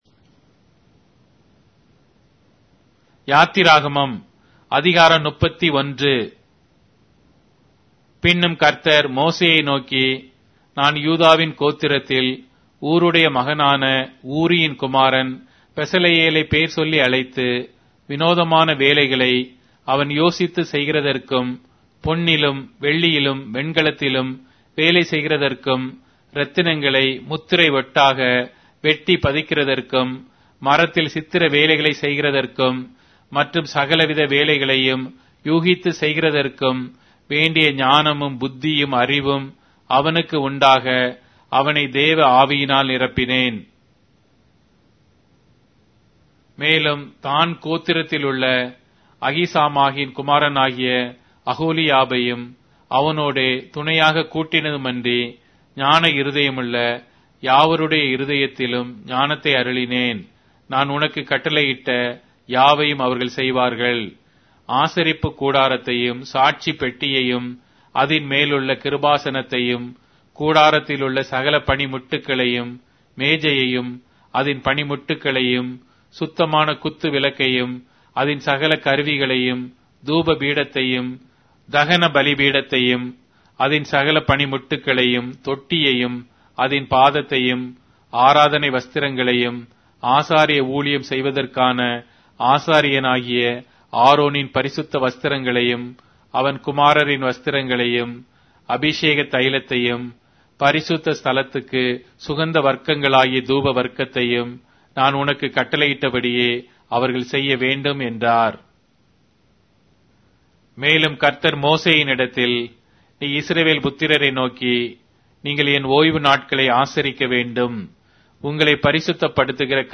Tamil Audio Bible - Exodus 13 in Tov bible version